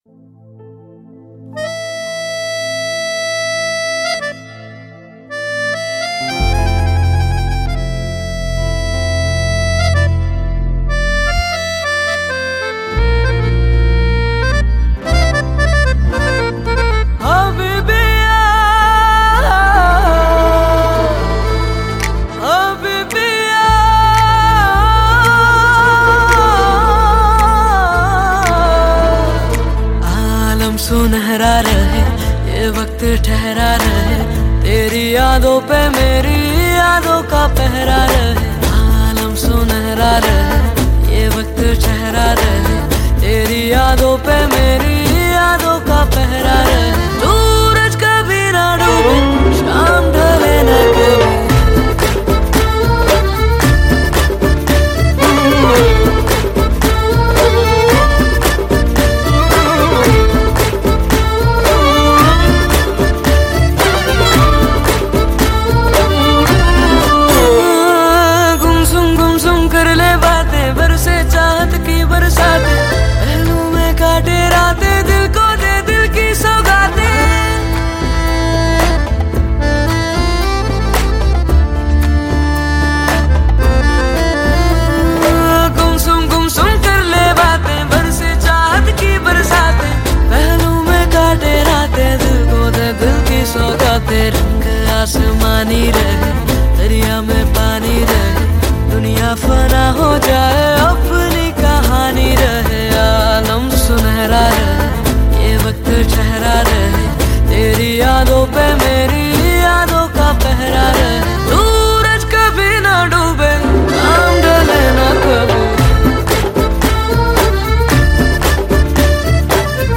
Hindi Pop Album Songs